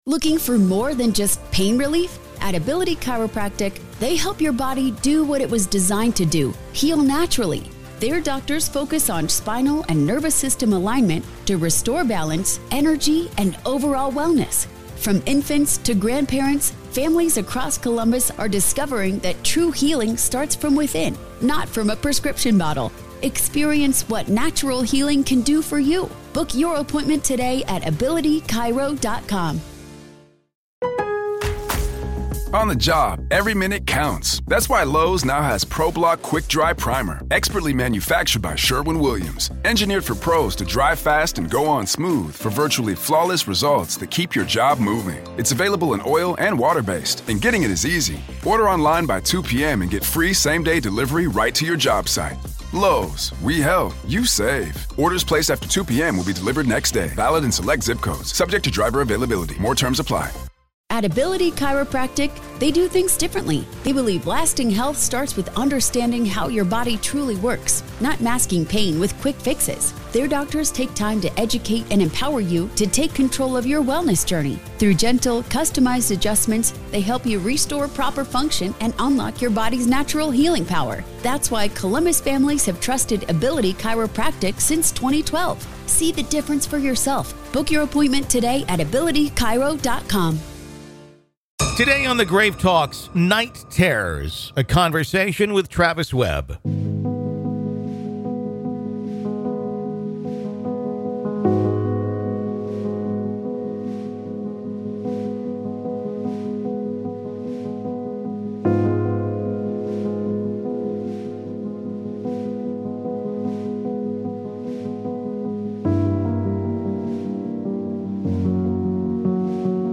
PART 2 - AVAILABLE TO GRAVE KEEPERS ONLY - LISTEN HERE In part two of our interview, available only to Grave Keepers , we discuss: What is the story and history of the haunted Madison Seminary?